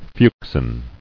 [fuch·sin]